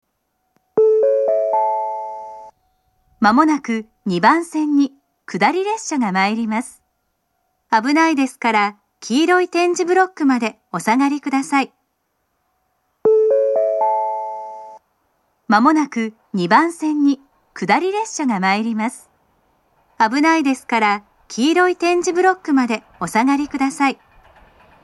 首都圏でも聞くことのできる仙石型の放送で、２回流れます。
接近放送前のチャイムが上下で異なるのはそのままです。
２番線接近放送